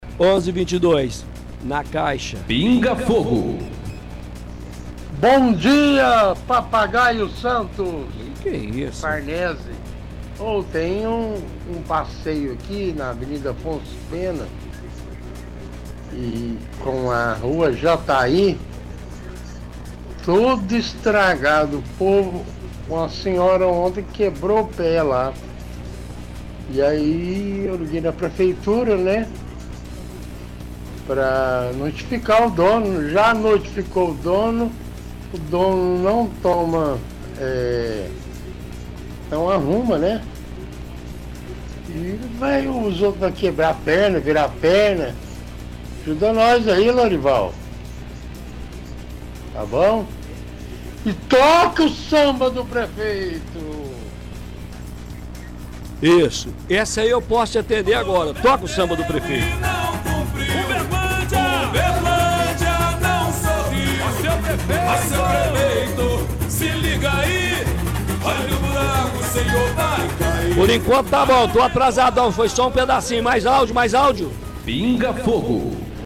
– Ouvinte reclama de calçada estragada, dizendo que já ligou na prefeitura, que disse que notificou o dono, mas até agora nada aconteceu.
– Música “prometeu e não cumpriu”.